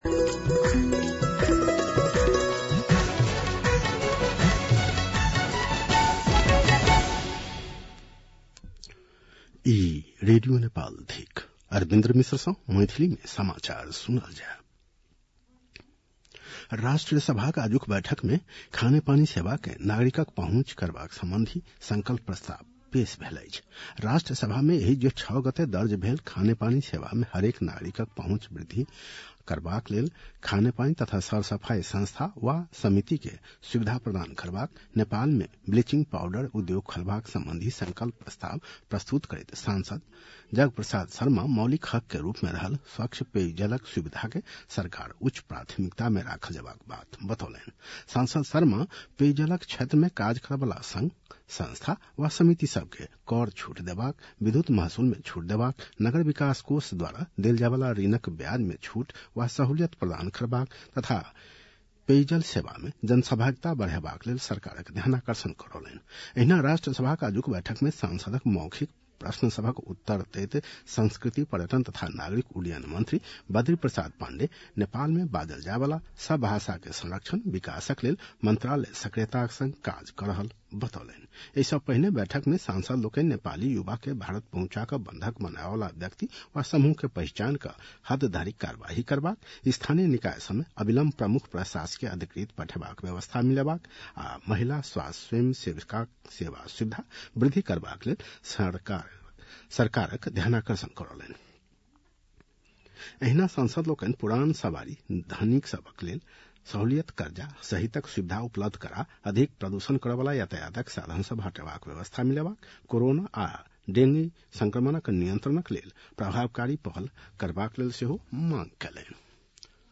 मैथिली भाषामा समाचार : १२ असार , २०८२